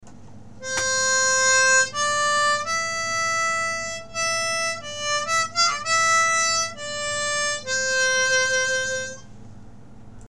We’re using a G major diatonic and playing in 1st position.